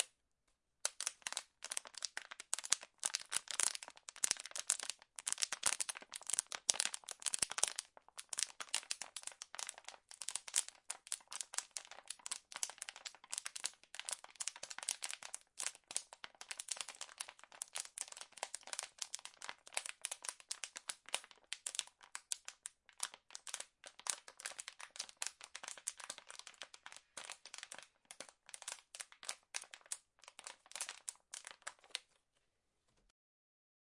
皱巴巴的塑料瓶
描述：弄皱塑料水瓶
Tag: 塑料瓶 压皱